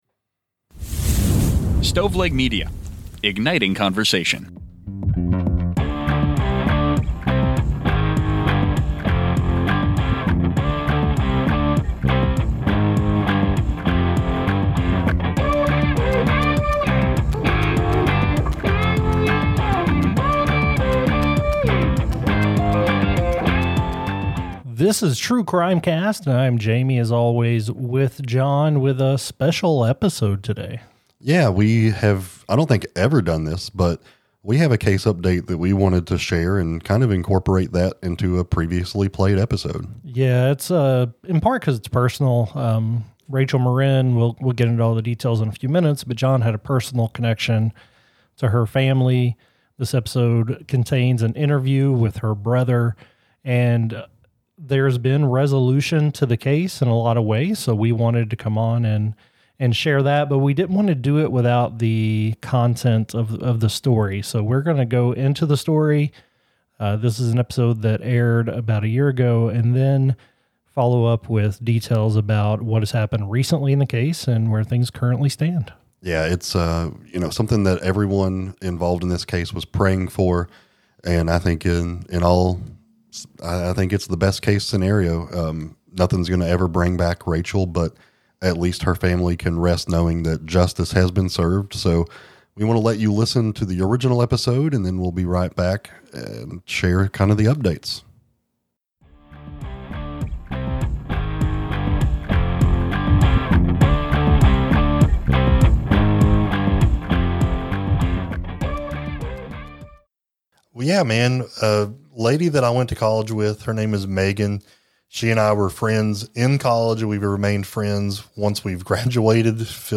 Society & Culture, Personal Journals, Documentary, True Crime